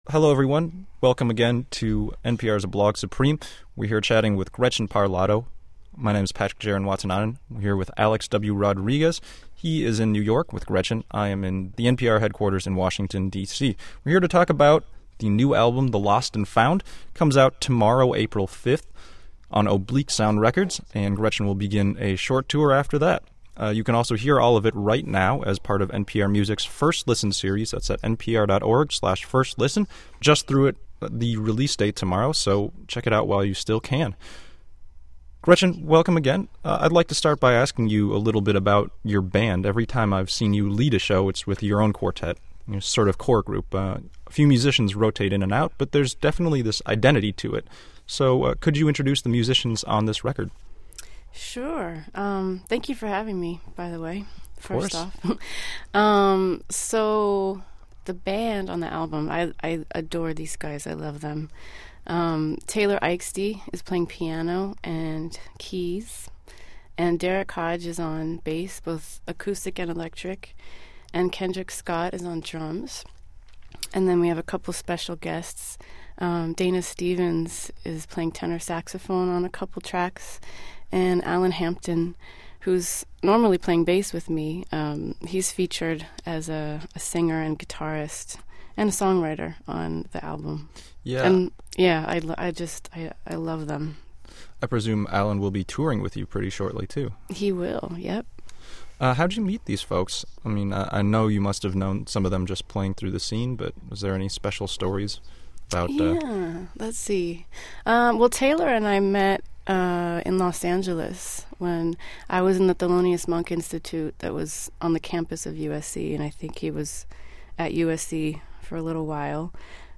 Interview And Live Chat With Gretchen Parlato